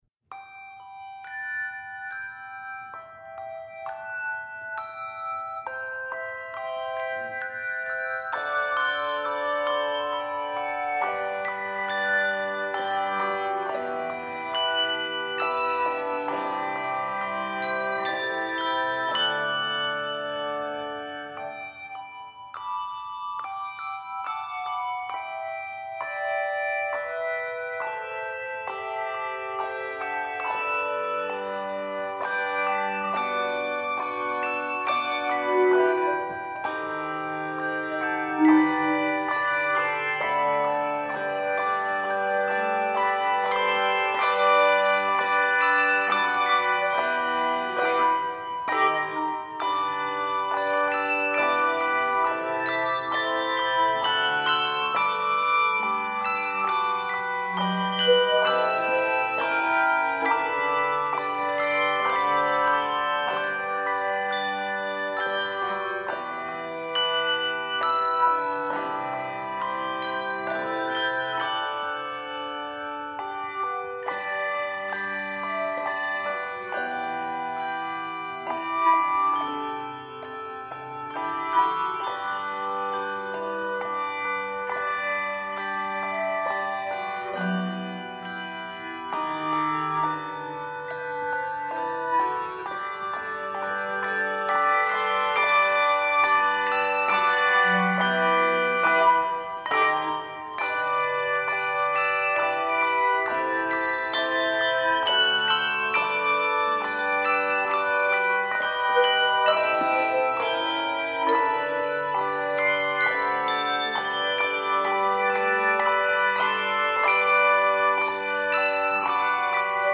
Key of G Major.